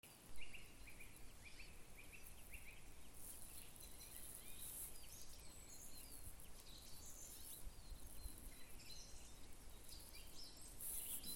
певчий дрозд, Turdus philomelos
Administratīvā teritorijaBabītes novads
СтатусПоёт